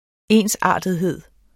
Udtale [ ˈeˀnsˌɑˀdəðˌheðˀ ]